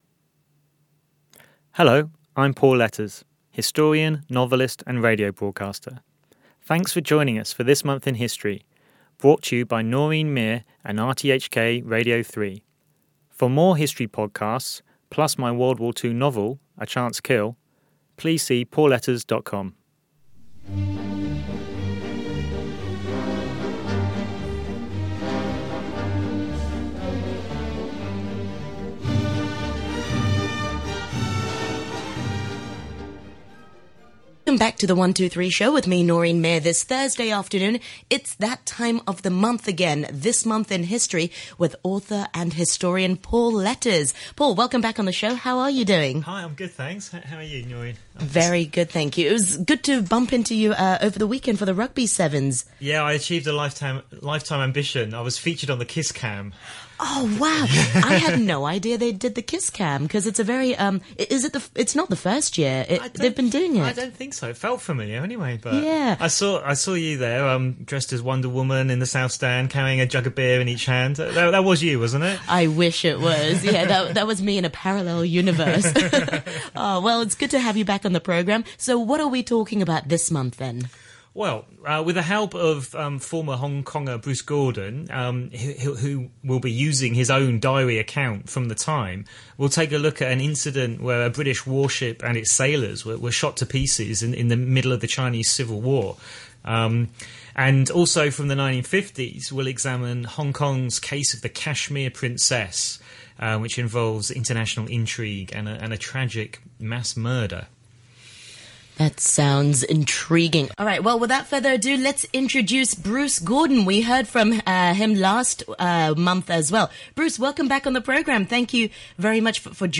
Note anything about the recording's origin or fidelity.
What have Fidel Castro, Taiwanese terrorists, a Kashmir Princess and HMS Amethyst got in common? On this show, our special guest, live from Kentucky USA, explains all. Recorded at Radio Television Hong Kong Studios, Broadcast Drive, Kowloon, Hong Kong.